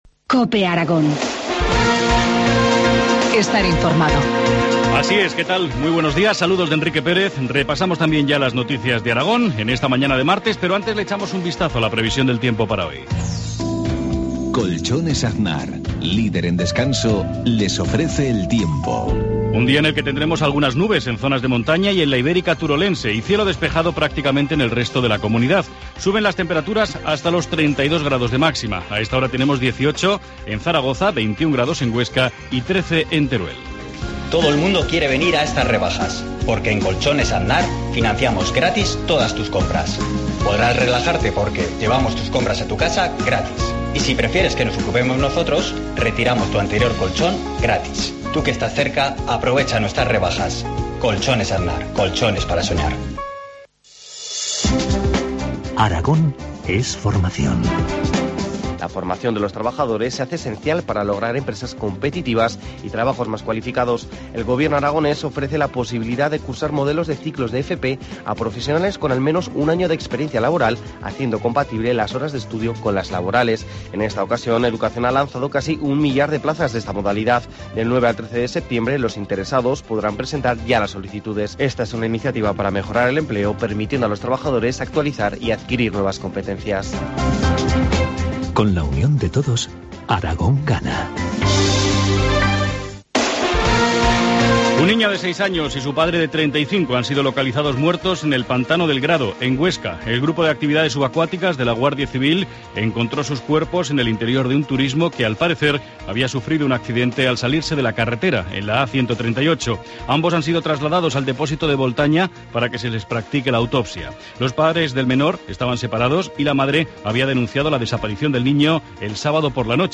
Informativo matinal, martes 3 de septiembre, 7.53 horas